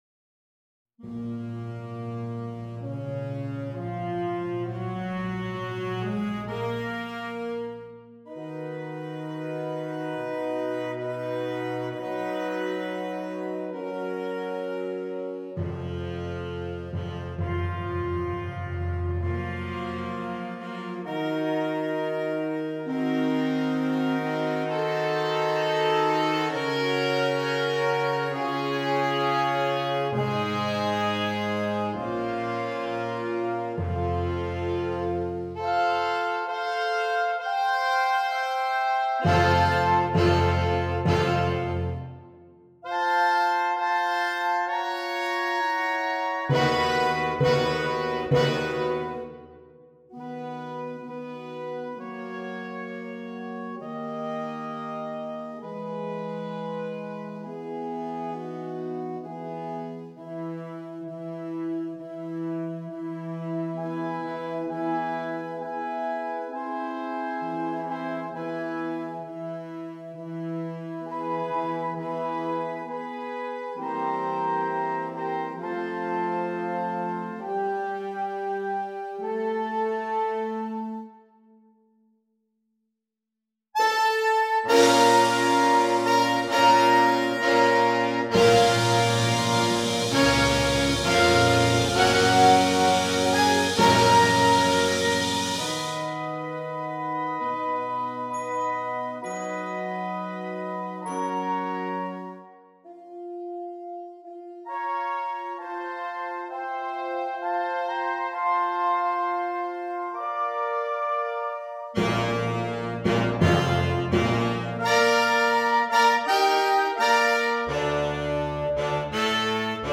• Saxophone Ensemble